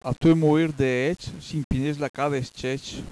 Se hai un browser che supporta i file .wav, cliccando sui proverbi scritti in bergamasco potrai ascoltarne anche la pronuncia, almeno per i primi 80 .